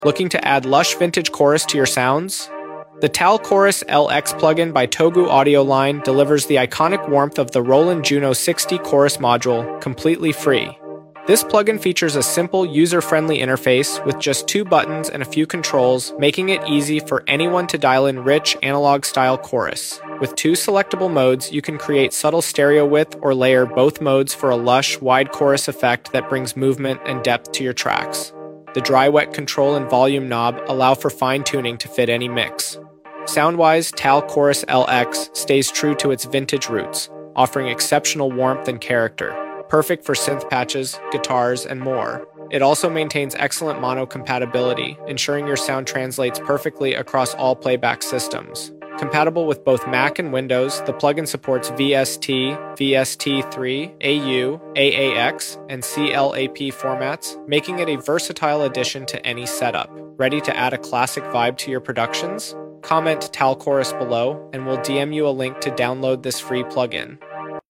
🎶 Add lush, JUNO-60 vibes to your tracks with TAL-Chorus-LX: • Iconic Roland warmth • Simple, user-friendly interface • 2 modes for subtle to wide effects • Perfect for synths, guitars & more • Mac & Windows compatible Ready for that classic analog chorus?